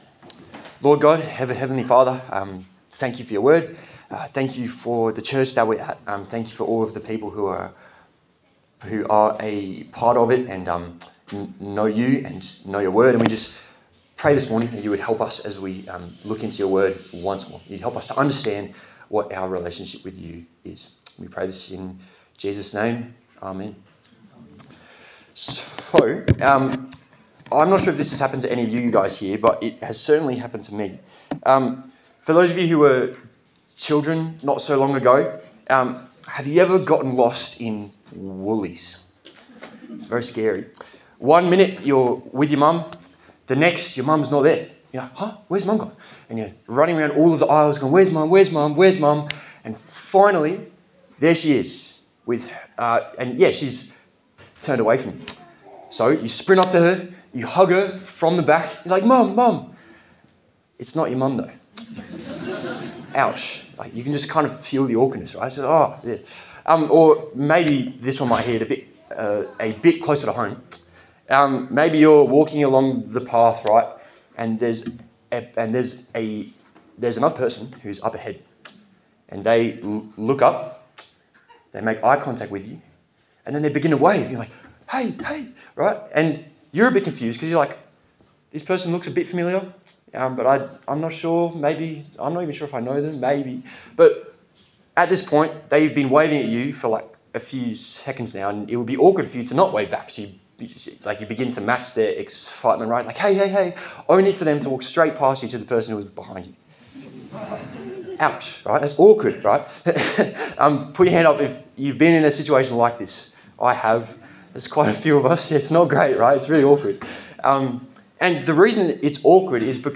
Galatians Passage: Galatians 3:18-4:11 Service Type: Sunday Service